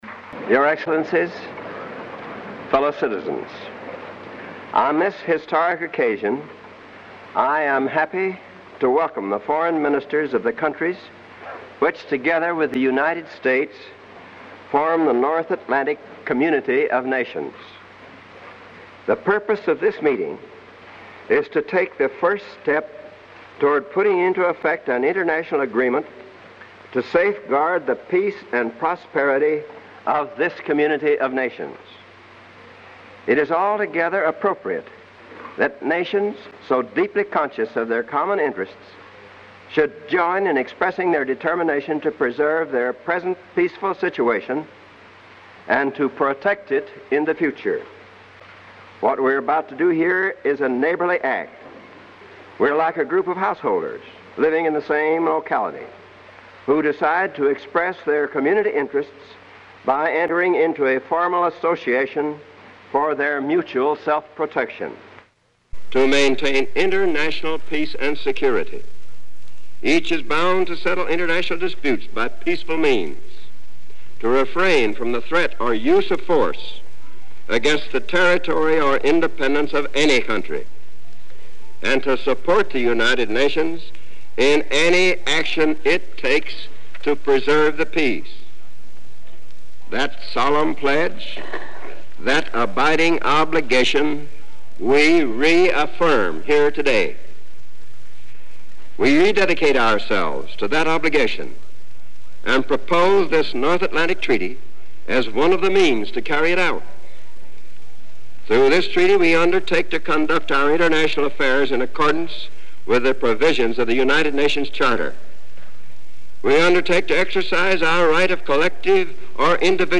Address at the NATO Treaty Signing Ceremony
delivered 4 April 1949, Departmental Auditorium, Washington, D.C.